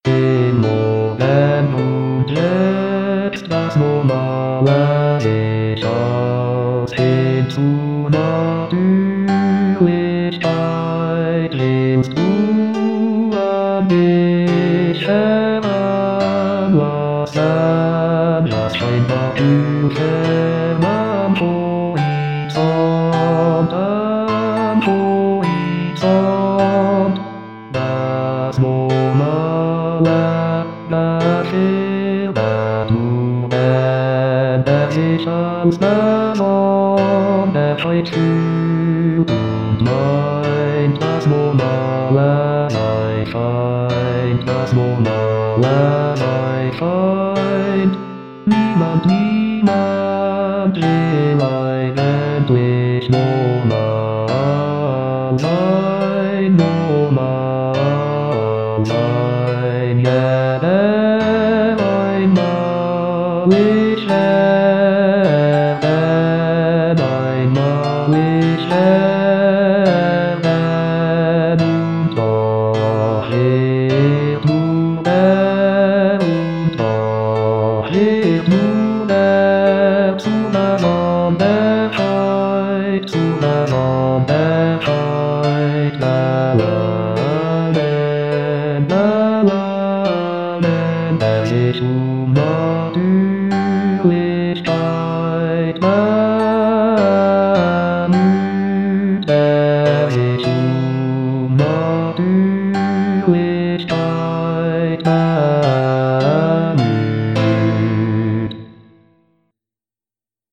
mp3-Aufnahme: Wiedergabe mit Gesang